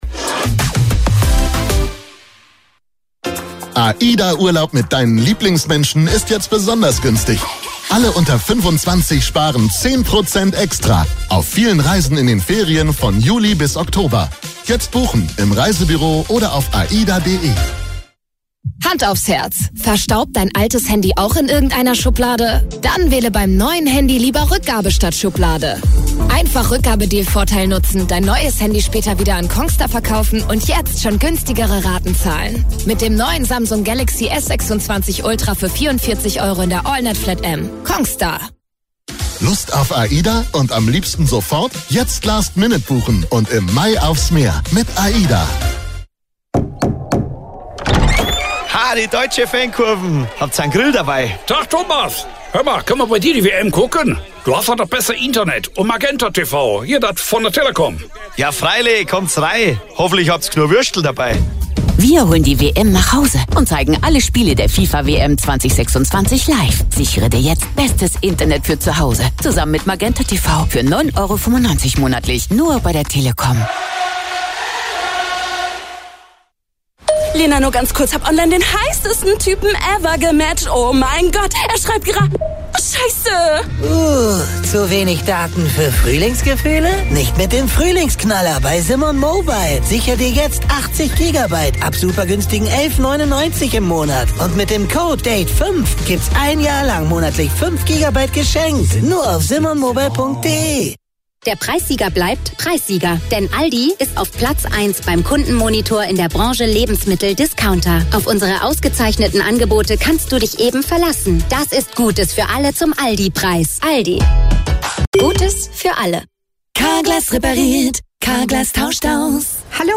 Hören Sie hier einen typischen Radiowerbeblock. – Spüren Sie die Lebendigkeit und die Verkaufsaktivierung?
Werbeblock.mp3